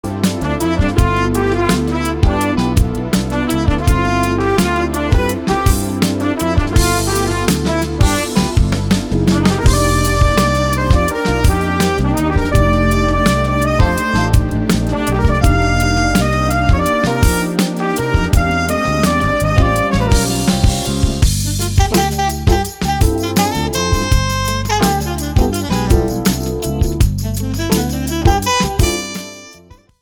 EASY LISTENING  (02.32)